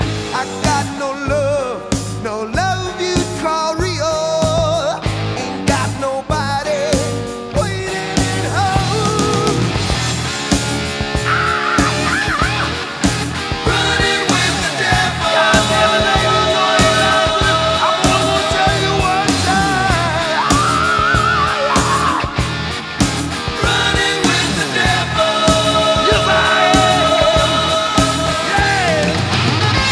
• Hard Rock
The song begins with a collection of car horns sounding.